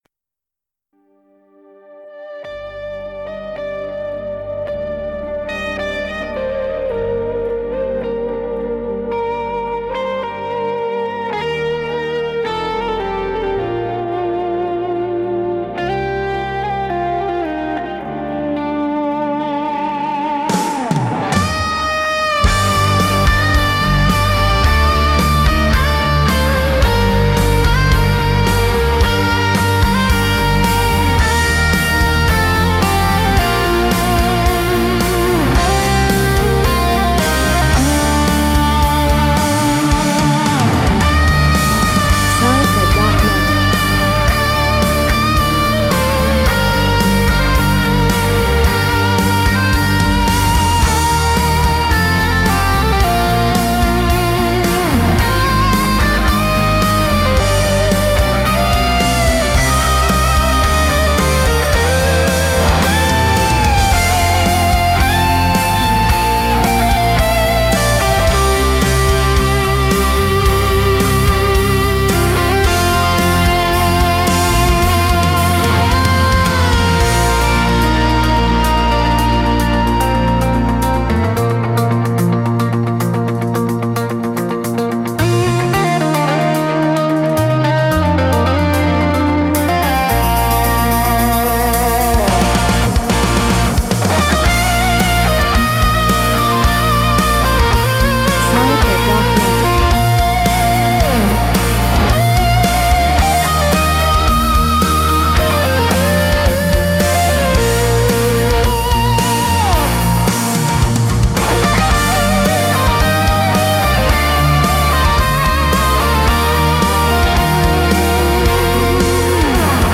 An Epic Glam Rock Odyssey